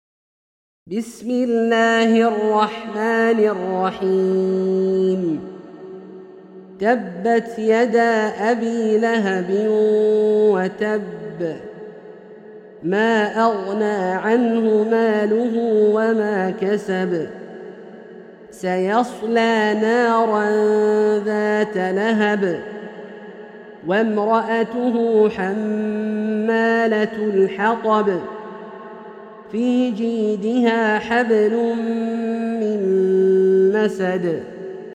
سورة المسد - برواية الدوري عن أبي عمرو البصري > مصحف برواية الدوري عن أبي عمرو البصري > المصحف - تلاوات عبدالله الجهني